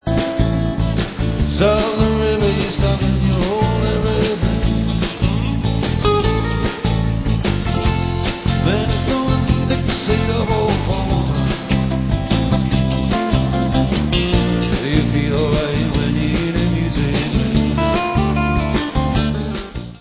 CD 2 CONTAINS LIVE RECORDINGS